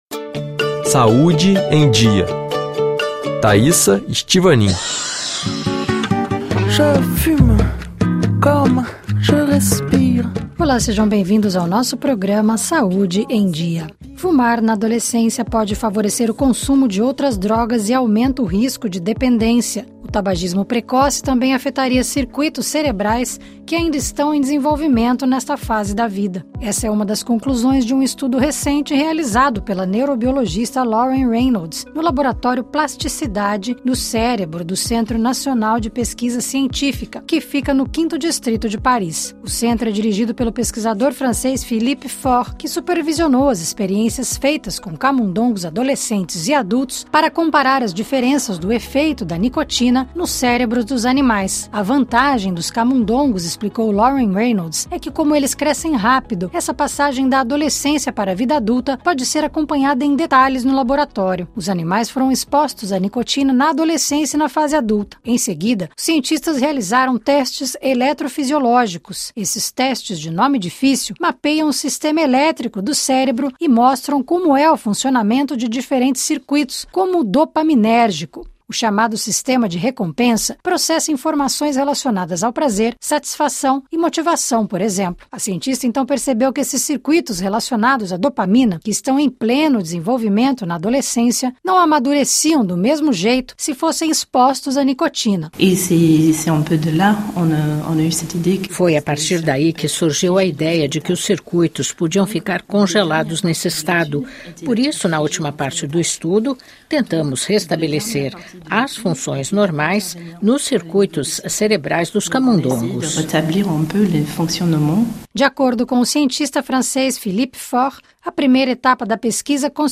Entrevistas e reportagens com especialistas sobre as novas pesquisas e descobertas na área da saúde, controle de epidemias e políticas sanitárias.